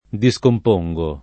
vai all'elenco alfabetico delle voci ingrandisci il carattere 100% rimpicciolisci il carattere stampa invia tramite posta elettronica codividi su Facebook discomporre [ di S komp 1 rre ] v.; discompongo [ di S komp 1jg o ], -ni — coniug. come porre